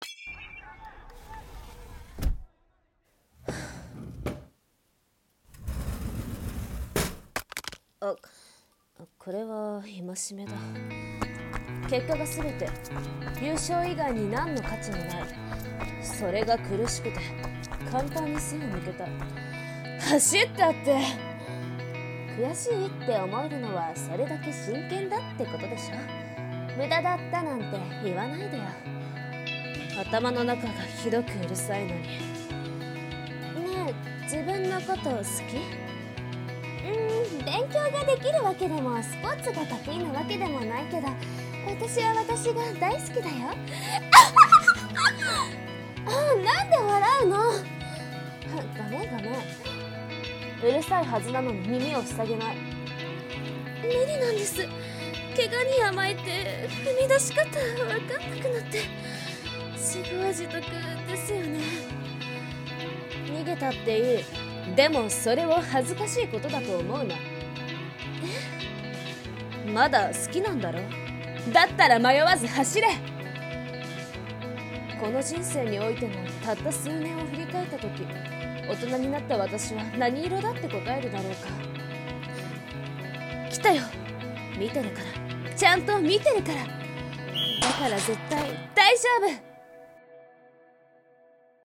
【声劇】